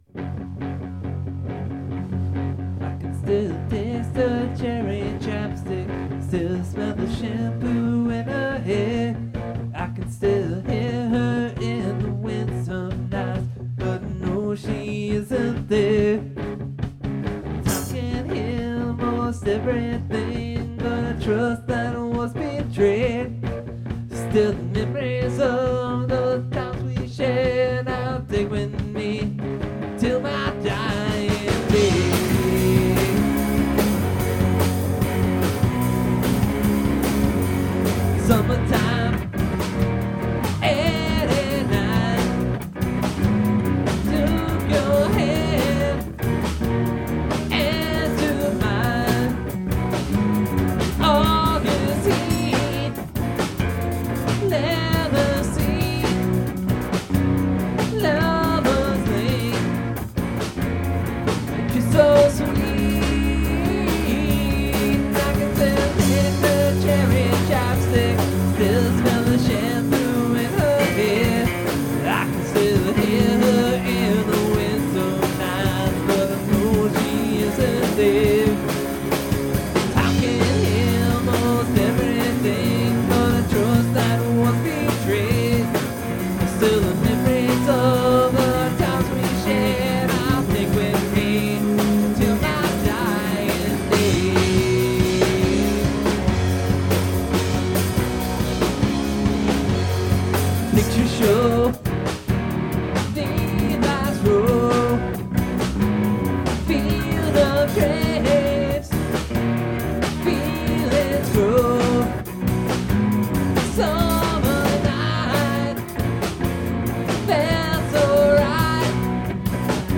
From band practice last night... this is a new song so take it for what it is.
Recorded via the 900 with one condenser mic (room) and a direct in with the vox.
Recorded on BR-900 with AT2020 and direct feed